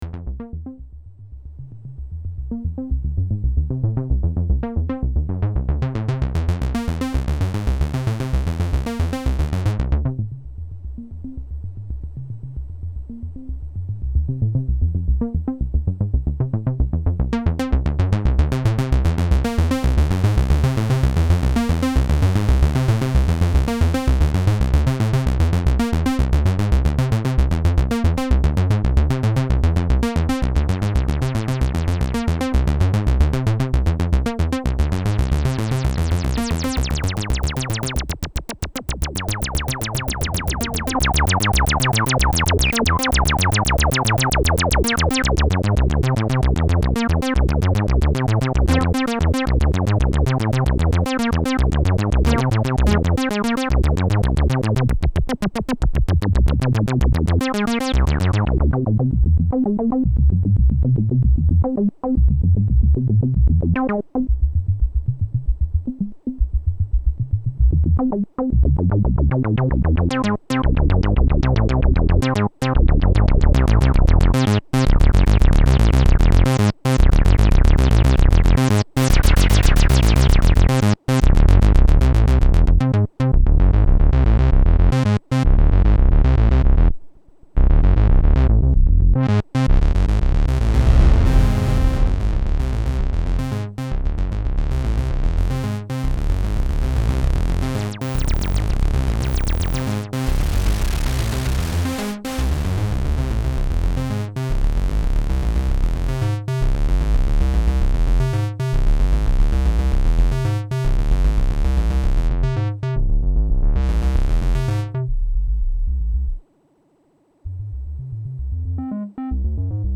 Wave Shapes sine pulse square saw noise
DETAILS soft nice sound and at least velocity but 30Hz LFO max speed. the little brother of the JX10.
SOUND 80ies pads and sounds
Quick & Dirty Audio Demo
Audio Demo JX03
boutique_jx03_quickdemo.mp3